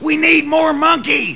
Slinky Dog's Sayings
Slinky Dog's voice belongs to Jim Varney.